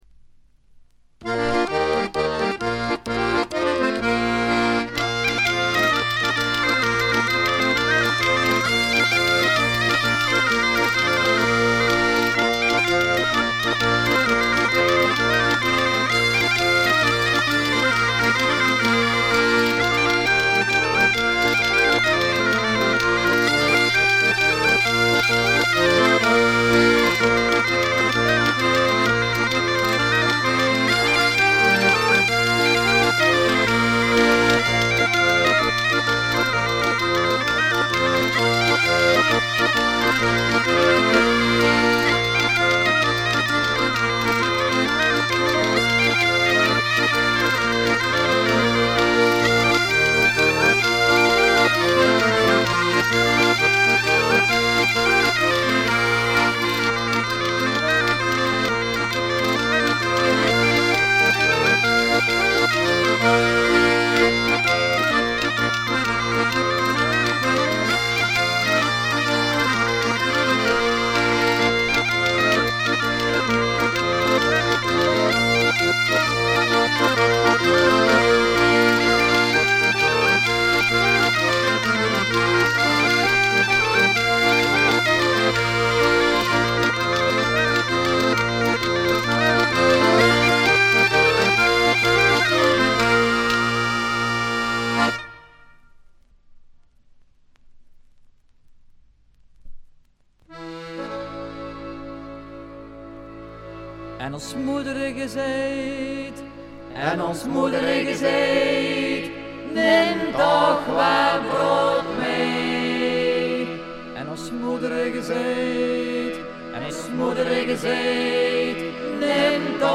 オランダのトラッド・バンド、男2女2の4人組。
試聴曲は現品からの取り込み音源です。